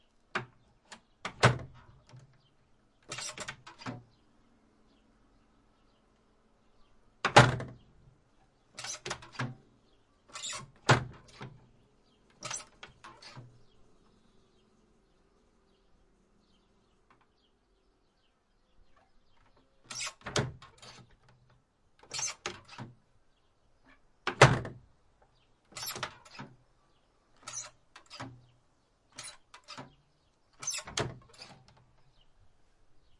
doorclose
描述：closing a medium door from the outside
标签： close door exterior impact outer plastic thud
声道立体声